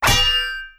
Melee Weapon Attack 17.wav